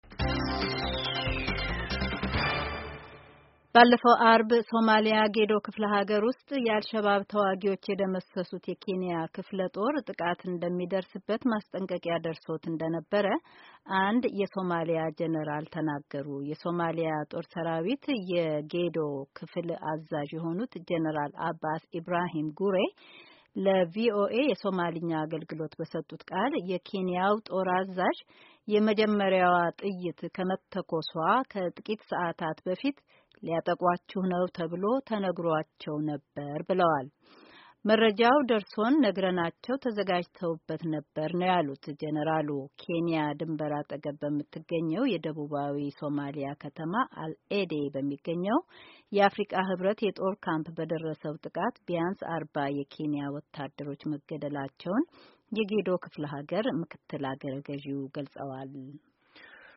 ኬንያ ድንበር አጠገብ በምትገኝ የደቡባዊ ሶማሊያ ከተማ ኤል አዴ በሚገኘው የአፍሪካ ህብረት የጦር ካምፕ በደረሰው ጥቃት ቢያንስ አርባ የኬንያ ወታደሮች መገደላቸውን የጌዶ ክፍለ ሀገር ምክትል አገረ ገዢ ገልጸዋል። የዜና ዘገባውን ከዚህ በታች ካለው የድምጽ ፋይል ያድምጡ።